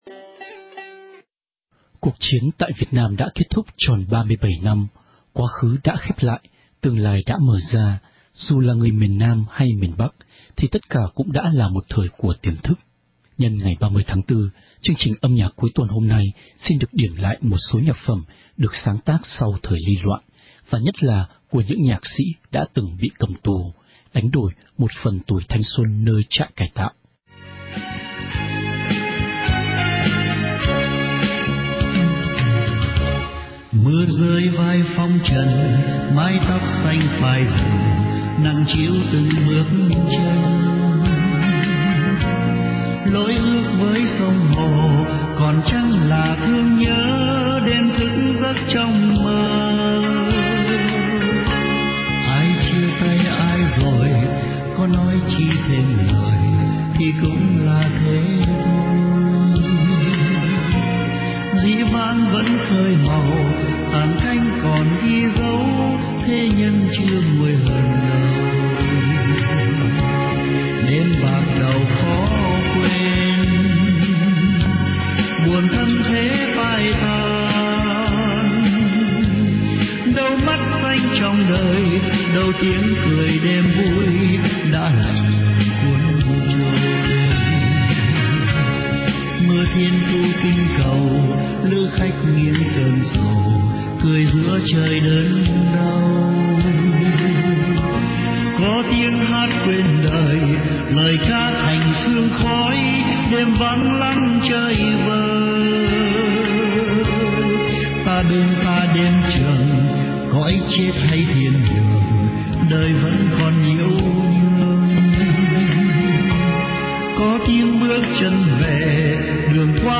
Nhân ngày 30/4, chương trình âm nhạc cuối tuần hôm nay, xin được điểm lại một số nhạc phẩm được sáng tác trong thời ly loạn và nhất là của những người nhạc sĩ đã từng bị cầm tù, đánh đổi một phần tuổi thanh xuân nơi trại cải tạo.
Nội dung của những bài hát này không chỉ nói đến sự mất tự do, kìm kẹp, mang âm hưởng bi tráng, mà lời ca nghe ai oán, mong một ngày được giải thoát để chim về được với trời xanh.